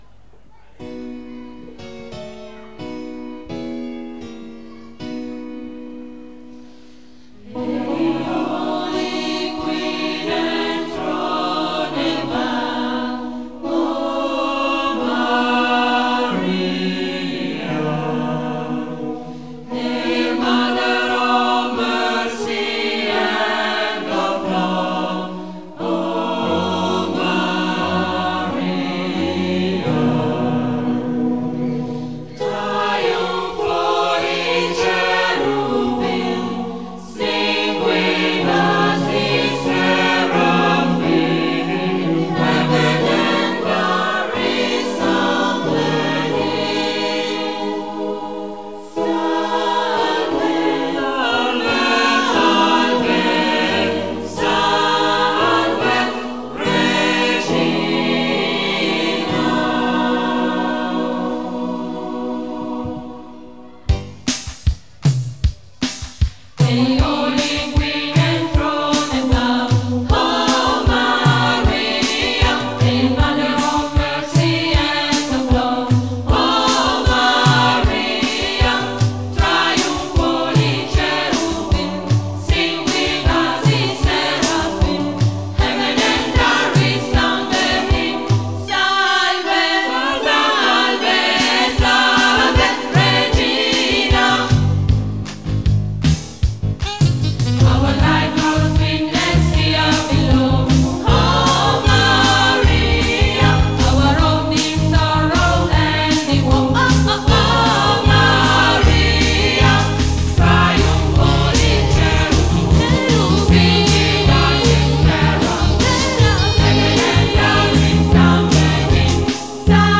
Il gruppo č composto da due voci maschili e sei femminili
Vi proponiamo ora l'ascolto di alcuni tra gli svariati brani proposti dal gruppo durante le serate, tutti cantati dal vivo.